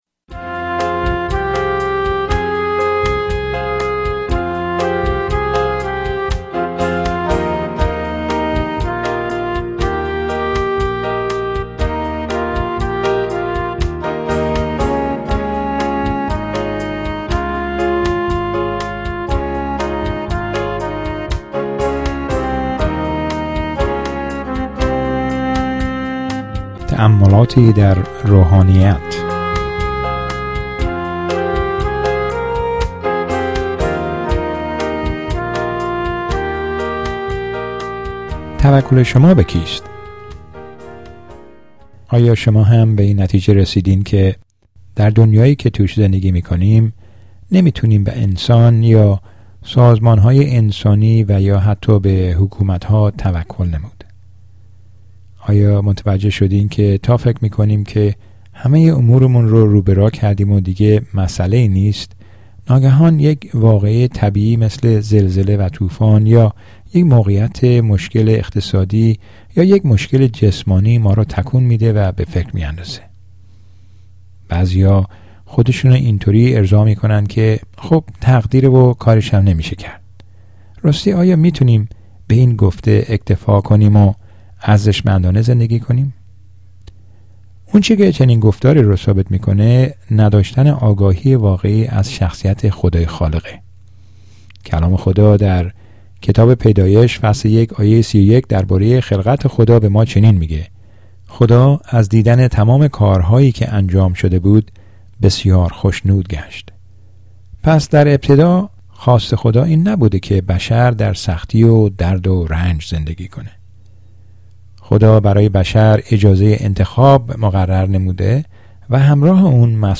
Spiritual Reflections